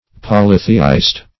Polytheist \Pol"y*the*ist\, n. [Cf. F. polyth['e]iste.]